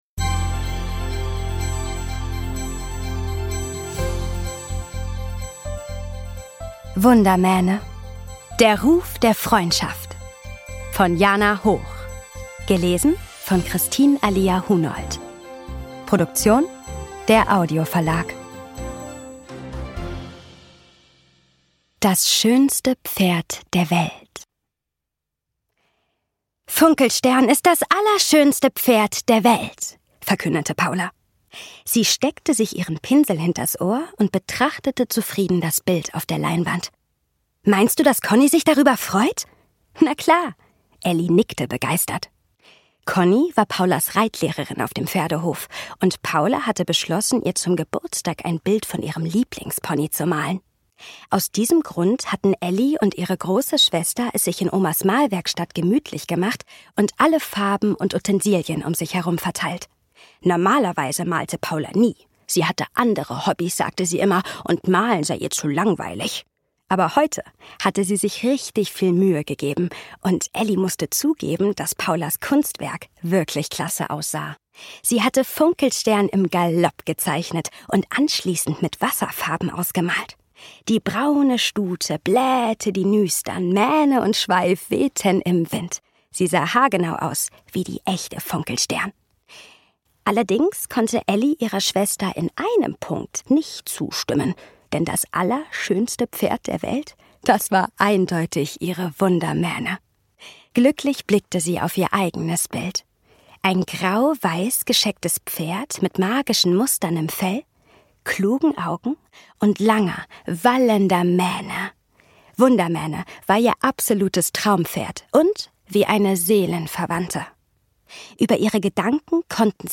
Ungekürzte Lesung mit Musik